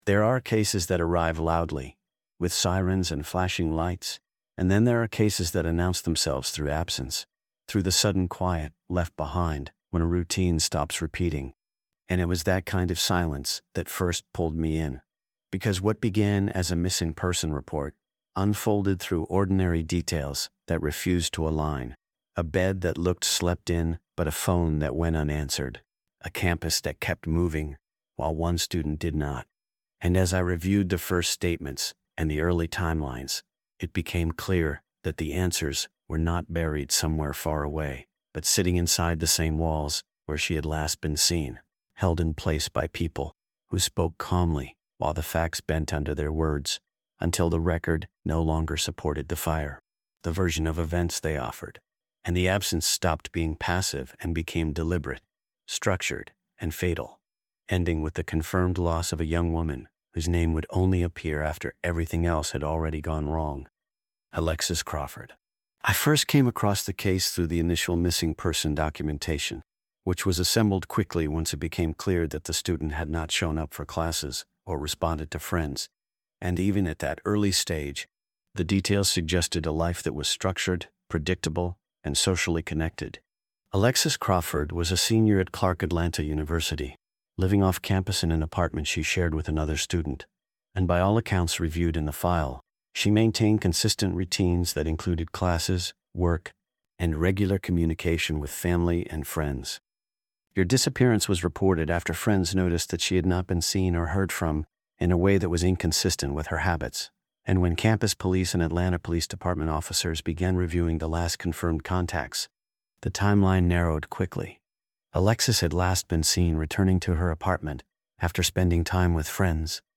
This true-crime audiobook